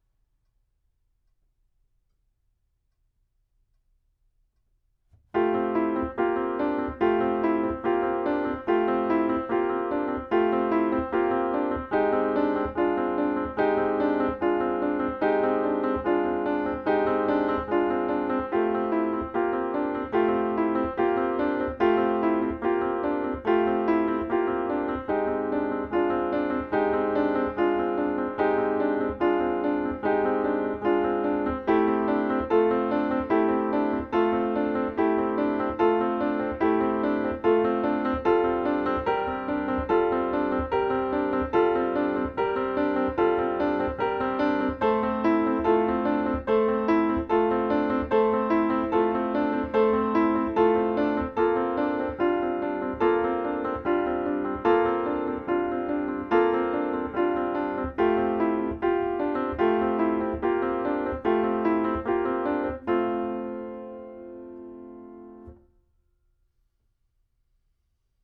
Piano IN_06.L.wav